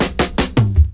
FILL 9    -R.wav